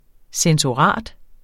Udtale [ sεnsoˈʁɑˀd ]